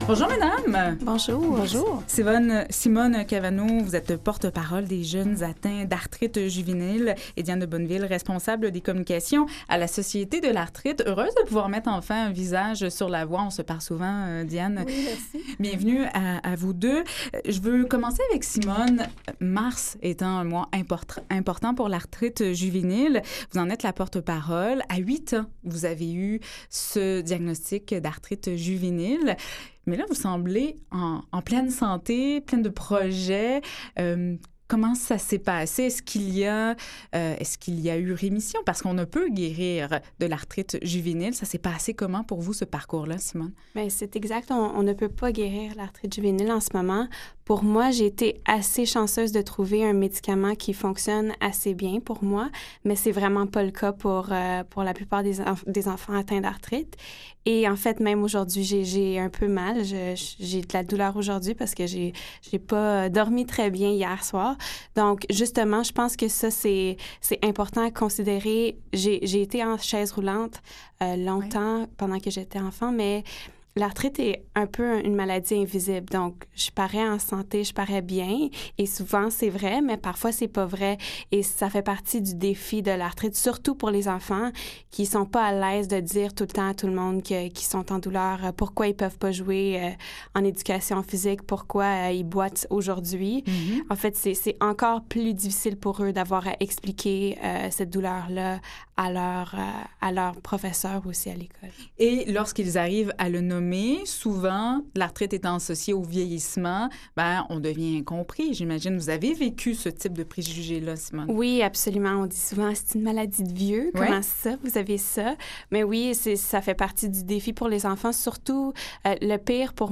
Mercredi 16 mars 2016 – Un magazine de services axé sur la promotion de la santé et de saines habitudes de vie.